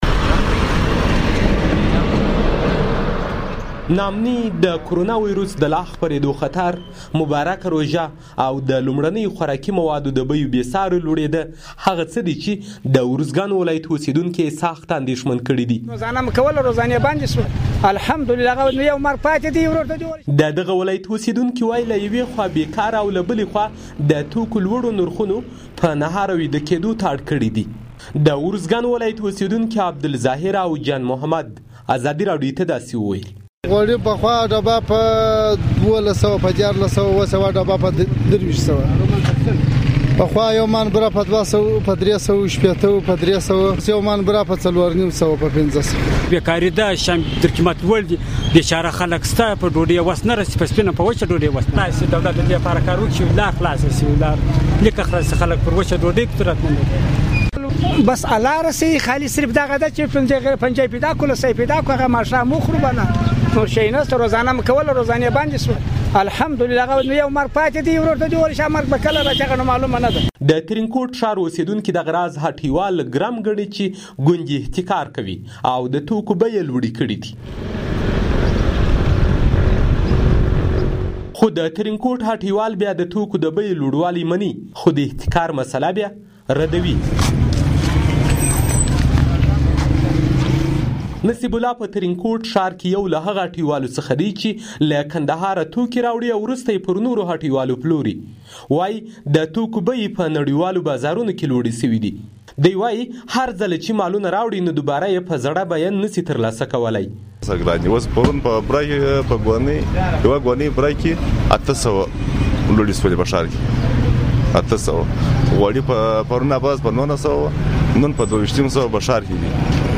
د ارزګان راپور